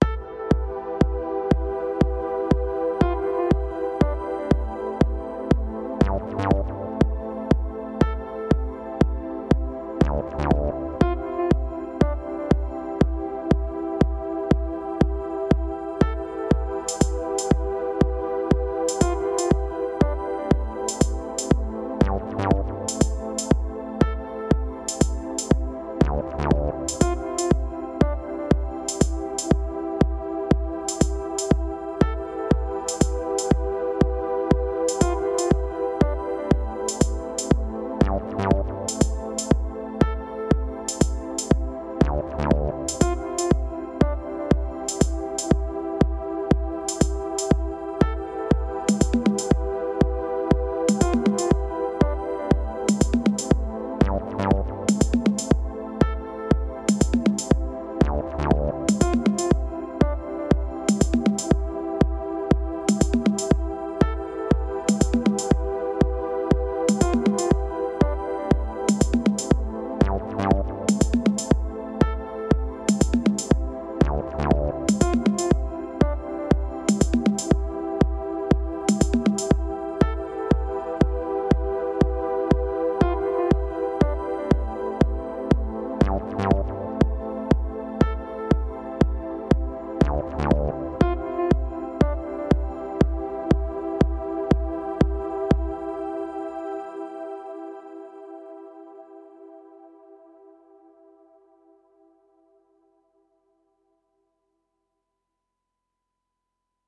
I have a Roland Fantom-06 workstation as my main instrument, and the Analog Heat MkII on the “Magnetic Master” setting makes the raw main output from the keyboard noticeably thicker-sounding.
I just tested using the internal limiter within the Fantom (TFX #34, Threshold: 35, Ratio: 1.5:1, Release: 32, Post Gain: +6dB, High Gain: -3dB) and found I could get to -19 LUFS (and around -12 on my DiGiGrid D audio interface) with these settings in front of a modified “Magnetic Master” preset on the AH and input sensitivity set to High.